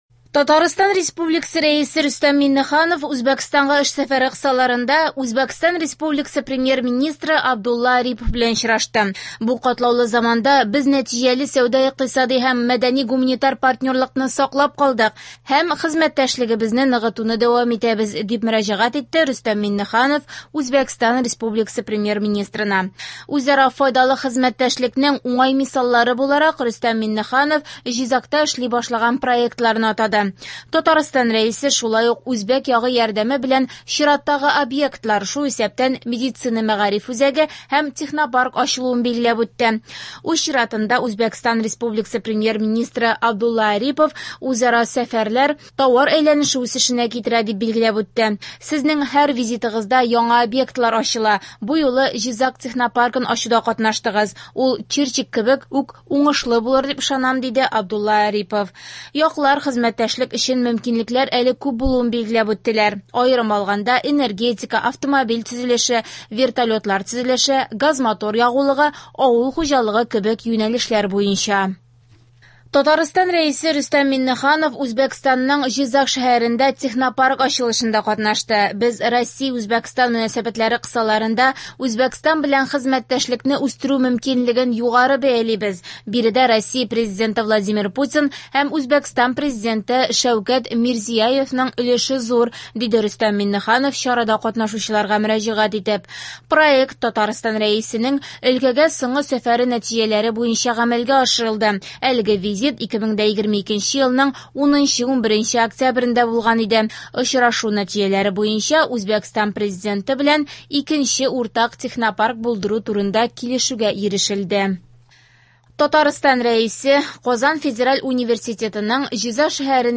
Яңалыклар (24.04.23)